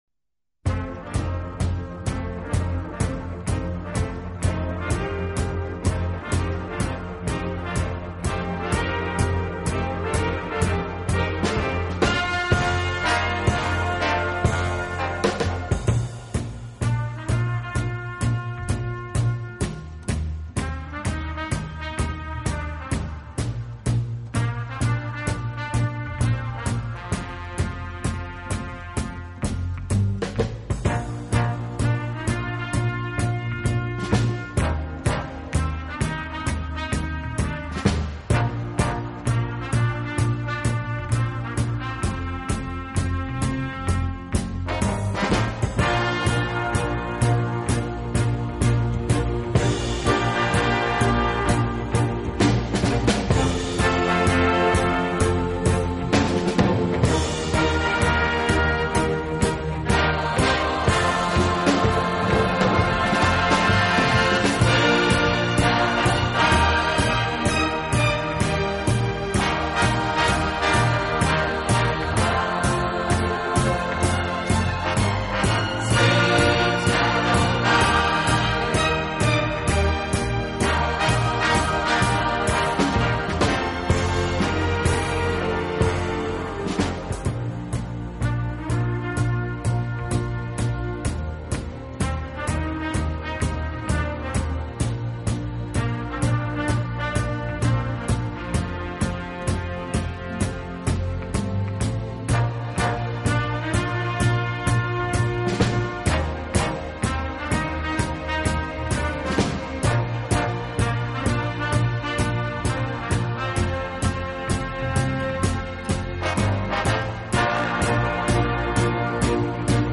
【轻音乐专辑】
小号的音色，让他演奏主旋律，而由弦乐器予以衬托铺垫，音乐风格迷人柔情，声情并
茂，富于浪漫气息。温情、柔软、浪漫是他的特色，也是他与德国众艺术家不同的地方。